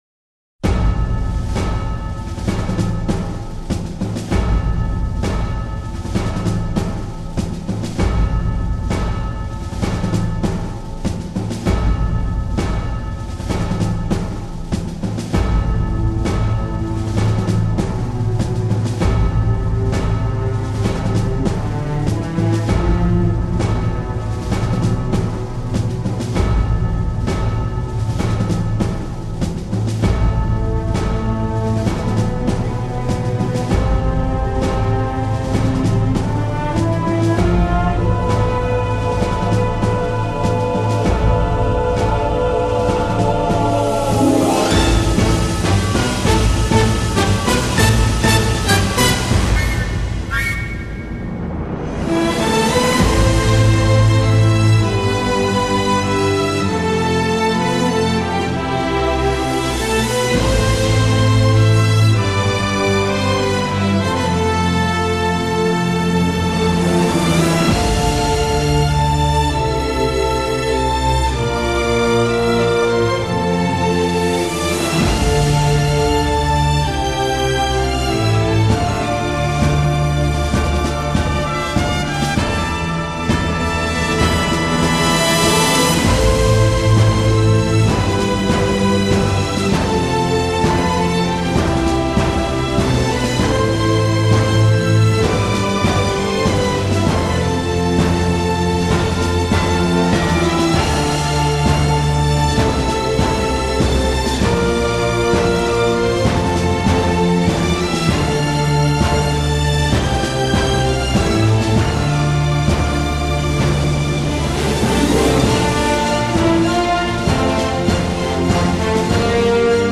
命运（钢琴版） 激动社区，陪你一起慢慢变老！